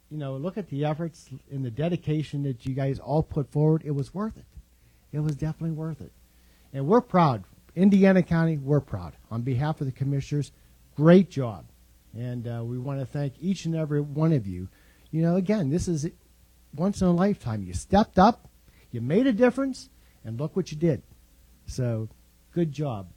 Chairman Mike Keith addressed the team, saying they brought great pride to Indiana County.
mike-keith.mp3